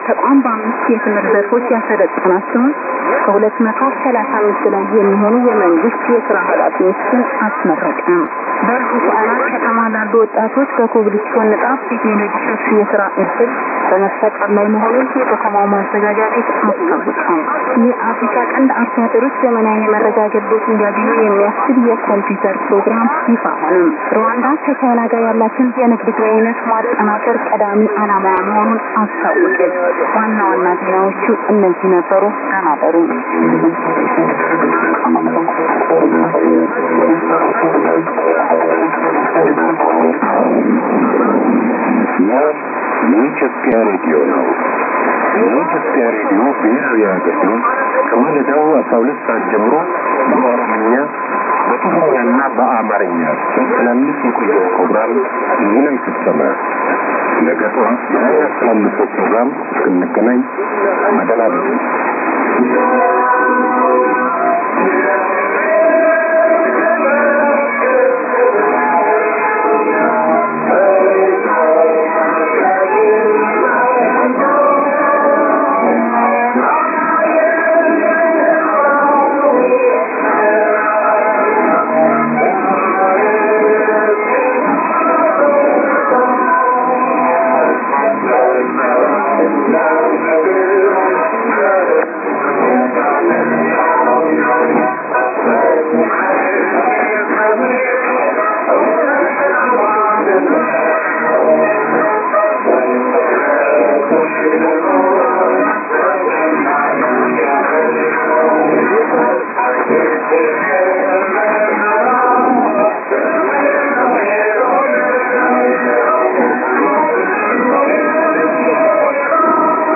・このＨＰに載ってい音声(ＩＳとＩＤ等)は、当家(POST No. 488-xxxx)愛知県尾張旭市で受信した物です。
ID: identification announcement
NA: national anthem